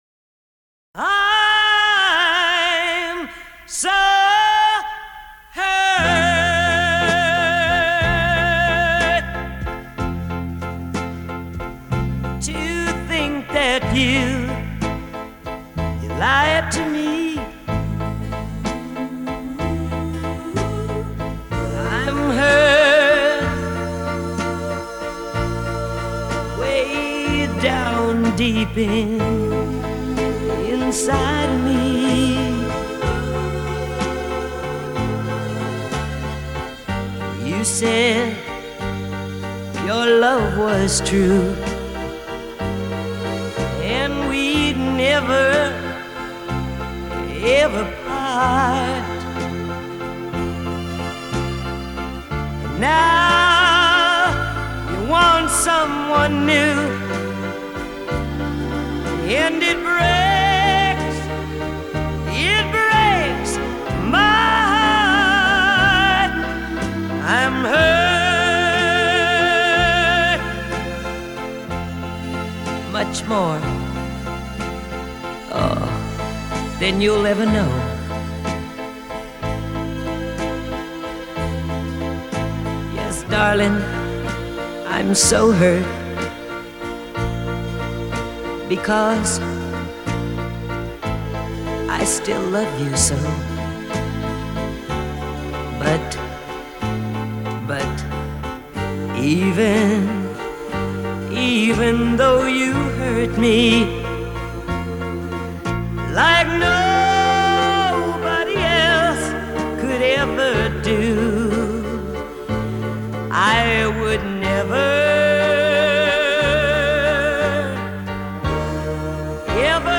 Recorded in Moscow 26.03.1968